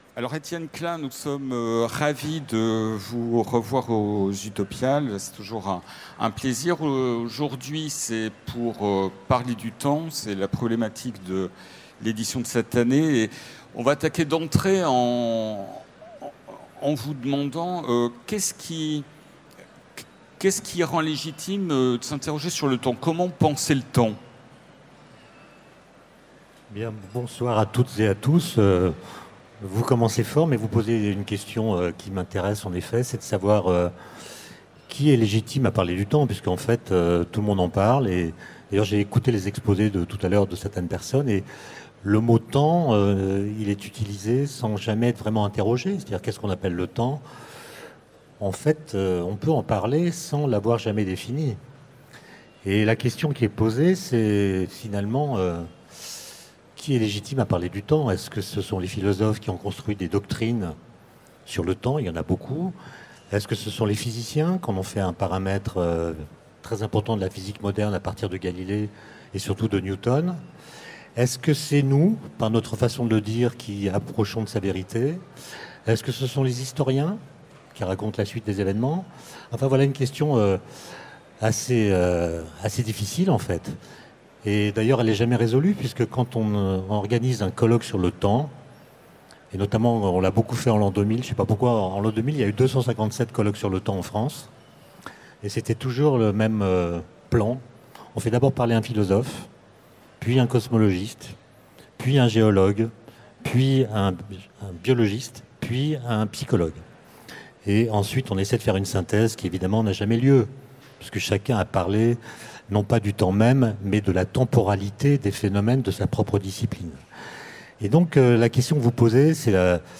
- le 05/11/2017 Partager Commenter Utopiales 2017 : Rencontre avec Étienne Klein Télécharger le MP3 à lire aussi Étienne Klein Genres / Mots-clés Rencontre avec un auteur Conférence Partager cet article